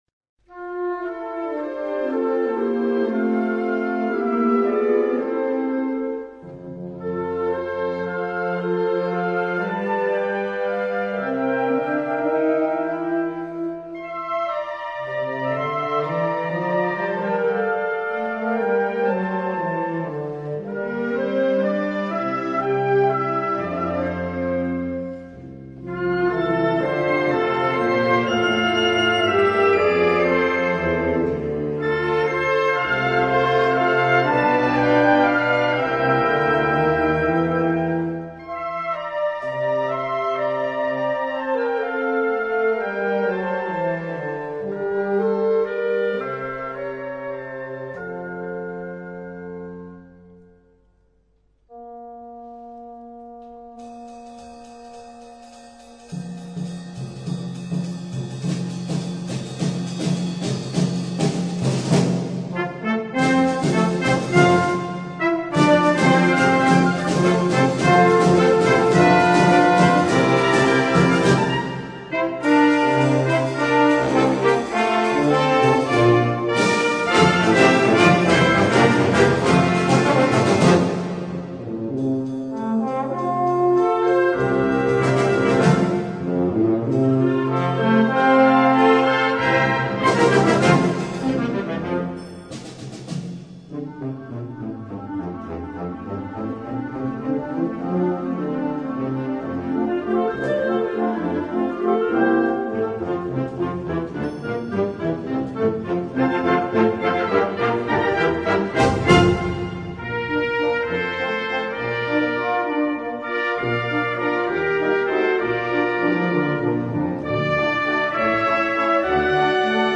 Brano da concerto
MUSICA PER BANDA